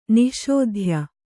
♪ nih śodhya